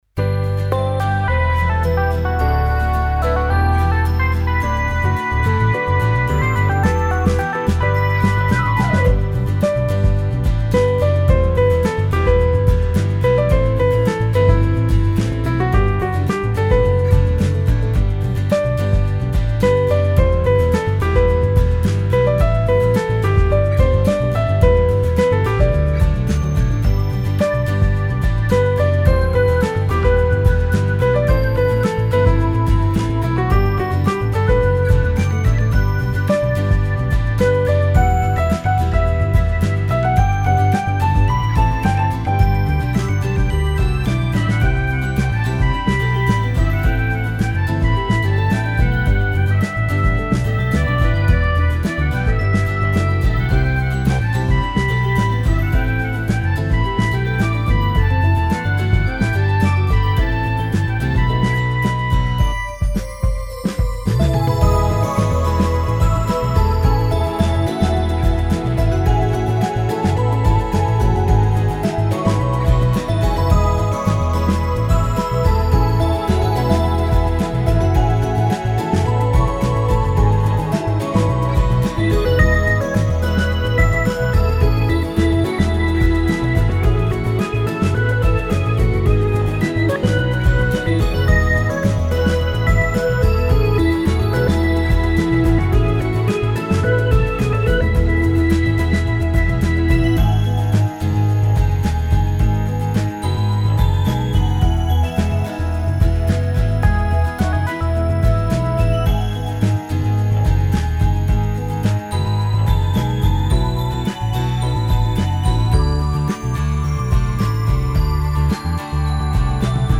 フリーBGM 街・拠点・村など 陽気・明るい
フェードアウト版のmp3を、こちらのページにて無料で配布しています。